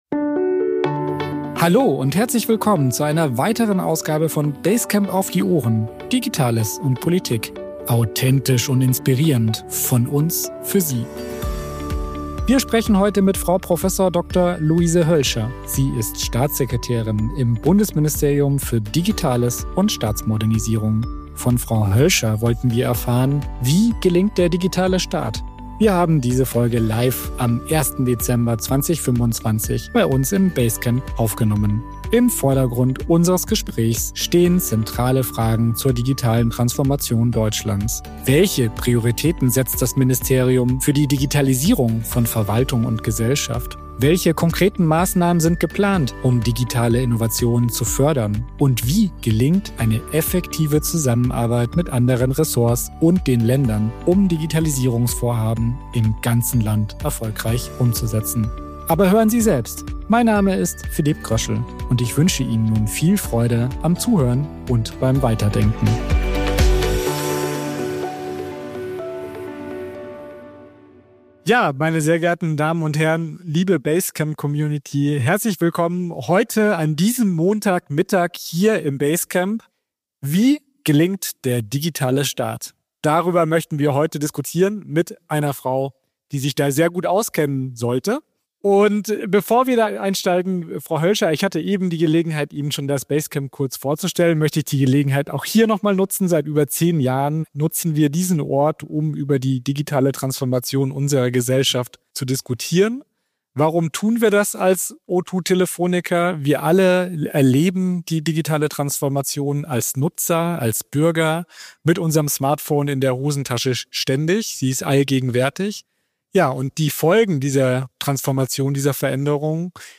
Prof. Dr. Luise Hölscher, Staatssekretärin im Bundesministerium für Digitales und Staatsmodernisierung gibt Einblicke in ihre neue Rolle und die strategischen Ziele der Bundesregierung.